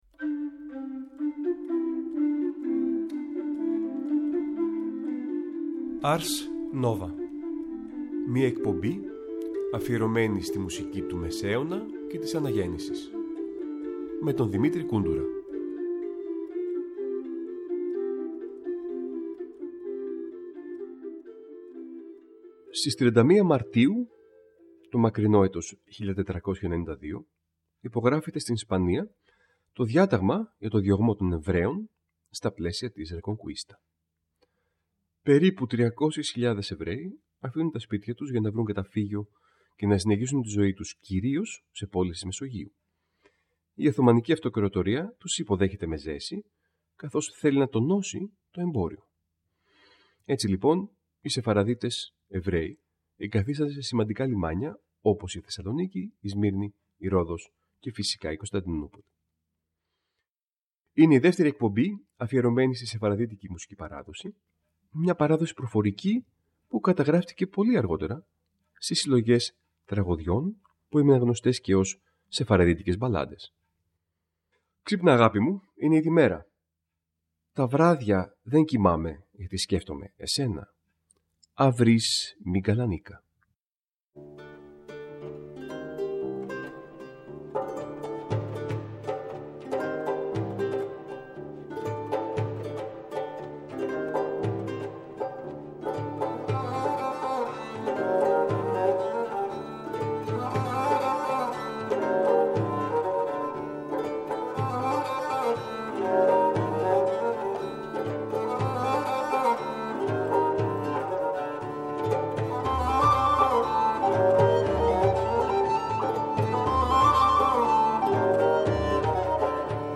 Νέα ωριαία μουσική εκπομπή του Τρίτου Προγράμματος που μεταδίδεται κάθε Τρίτη στις 19:00.